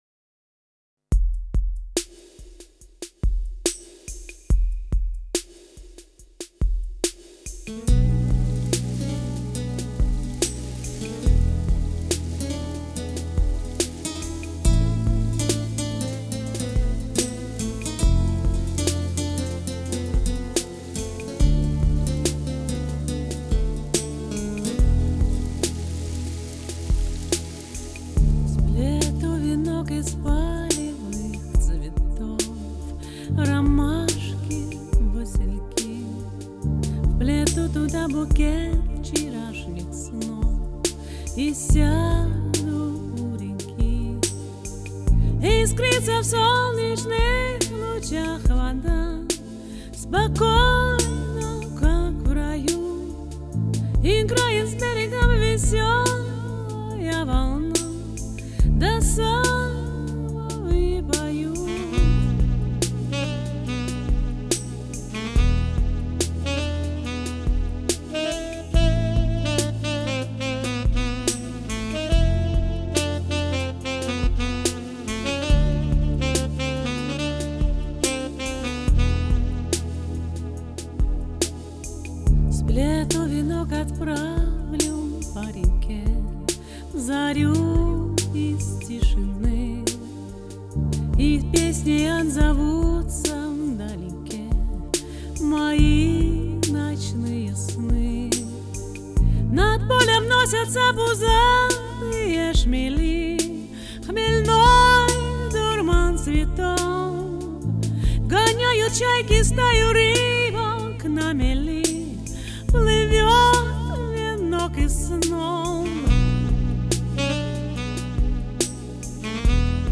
ревера многовато на голосе